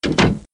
Play Door Close AIM - SoundBoardGuy
PLAY aim door close sound
door-close-aim.mp3